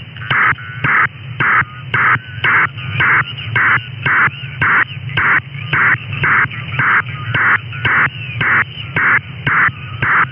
Modulation: PSK
Bandwidth: 1.2 kHz.